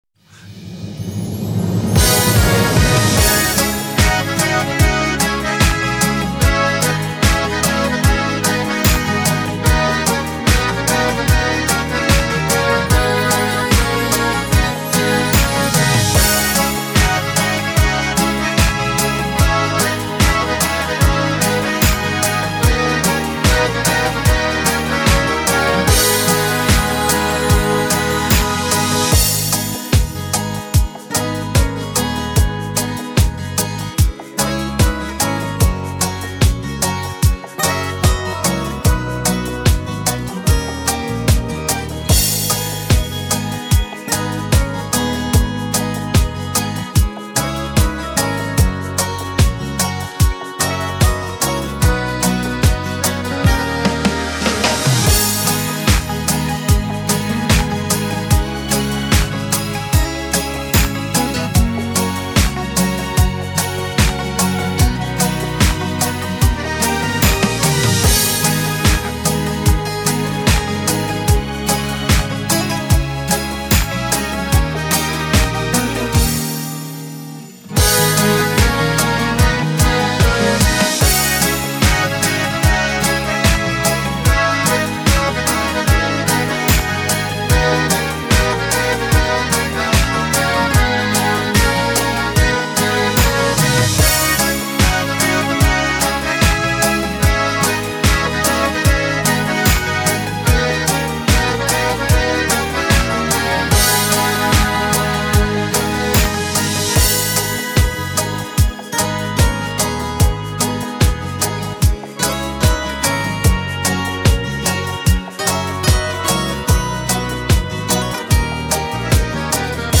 это минусовка